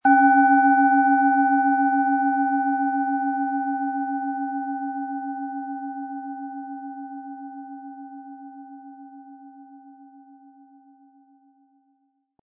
Planetenschale® Reden und Ausdrücken können & Geistige Wachheit mit Merkur, Ø 11,2 cm, 100-180 Gramm inkl. Klöppel
Wie klingt diese tibetische Klangschale mit dem Planetenton Merkur?
Unter dem Artikel-Bild finden Sie den Original-Klang dieser Schale im Audio-Player - Jetzt reinhören.
MaterialBronze